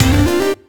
retro_collect_item_stinger_04.wav